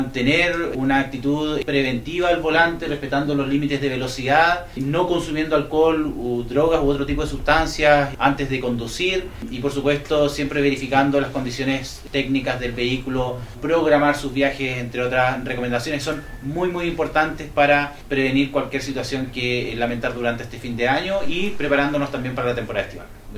El seremi de Transportes, Pablo Joost, llamó a mantener una actitud preventiva al volante, respetando los límites de velocidad y no consumir alcohol ni sustancias ilícitas antes de conducir.